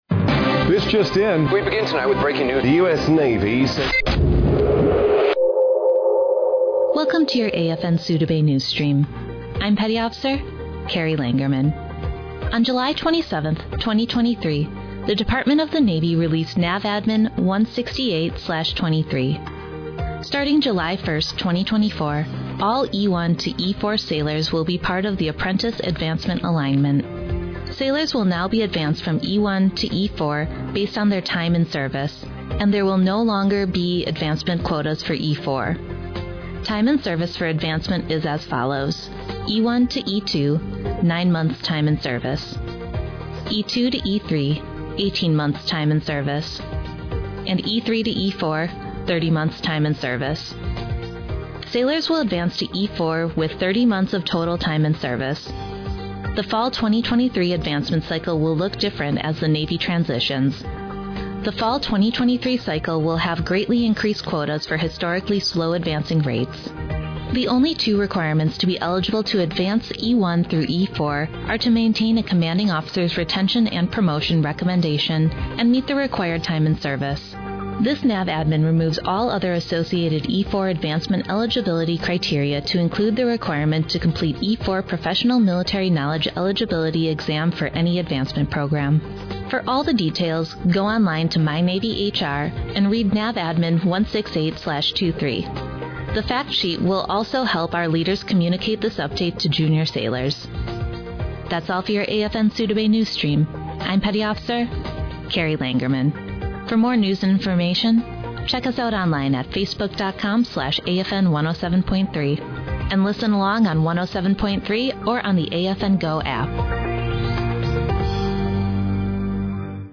230728-NSASOUDABAYRADIONEWS